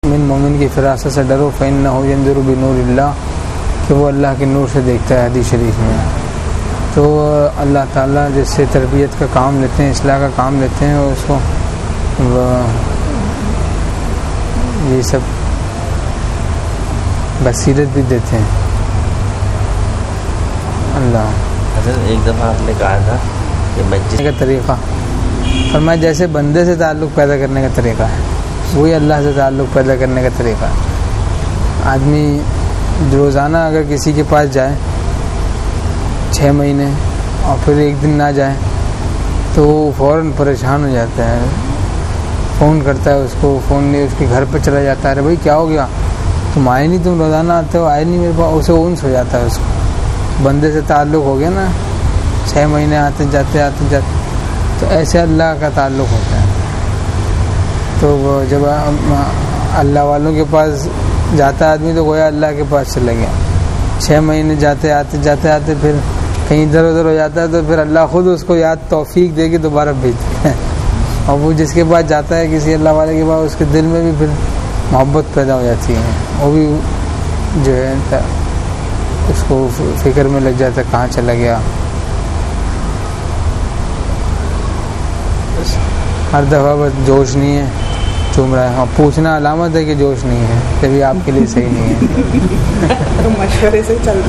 حضرت کی کورنگی میں مجلس، بعد نمازِ عشاء